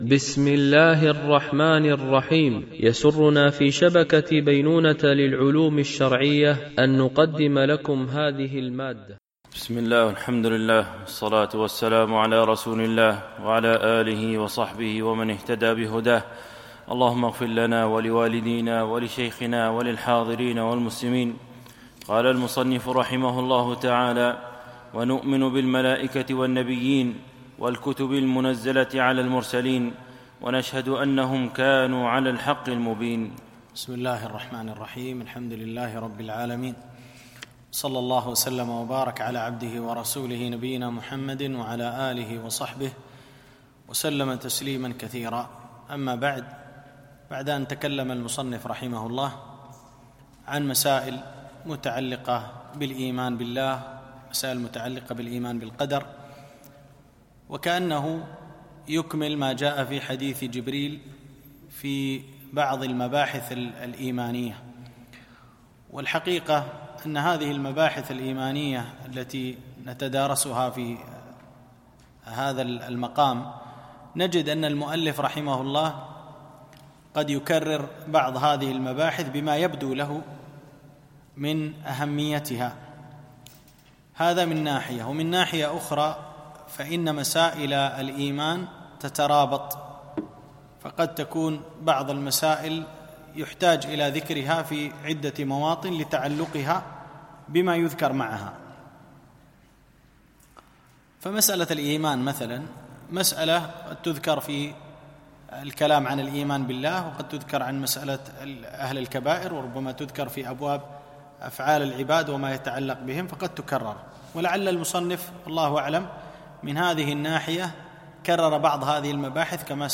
مباحث إيمانية - الدرس 7